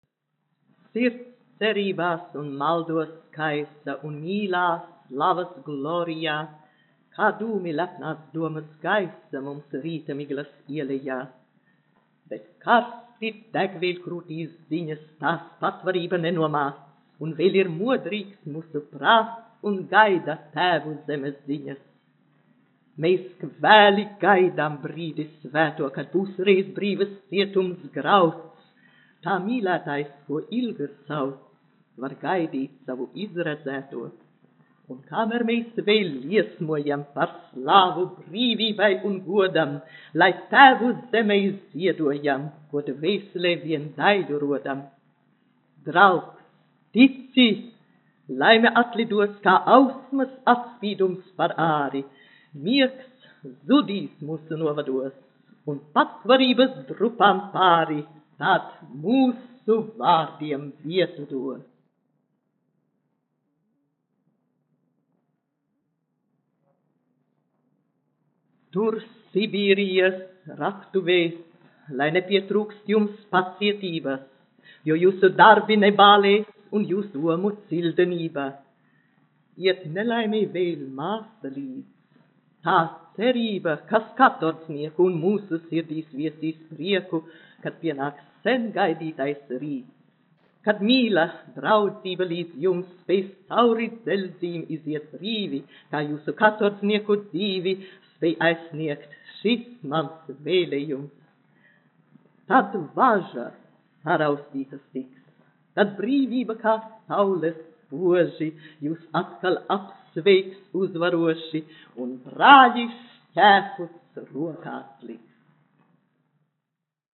[Lilita Bērziņa lasa A. Puškina dzeju]
Lilita Bērziņa, 1903-1983, izpildītājs
1 skpl. : analogs, 78 apgr/min, mono ; 25 cm
Skaņuplate
Latvijas vēsturiskie šellaka skaņuplašu ieraksti (Kolekcija)